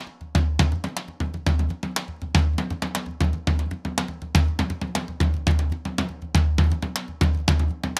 Surdo 1_Candombe 120_2.wav